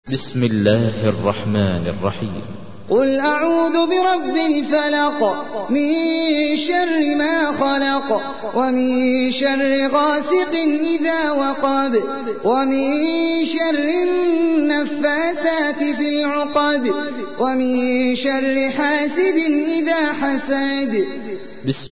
تحميل : 113. سورة الفلق / القارئ احمد العجمي / القرآن الكريم / موقع يا حسين